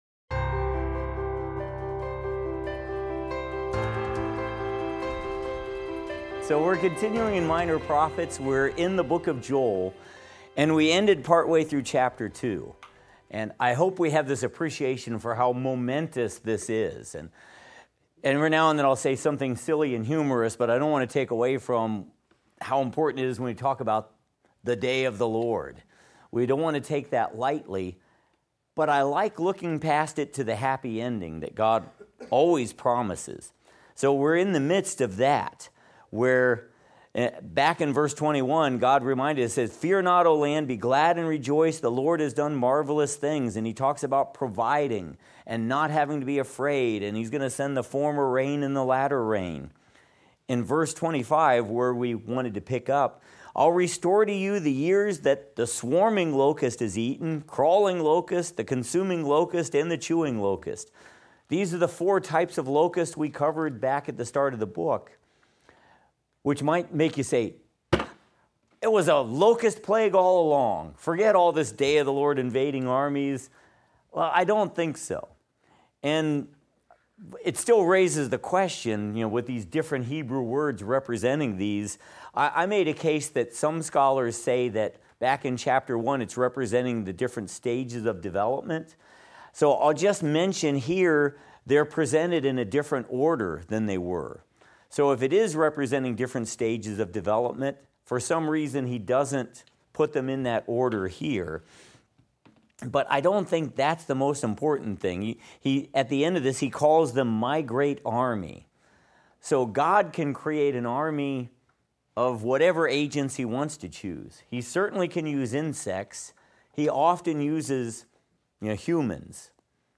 Minor Prophets - Lecture 8 - audio.mp3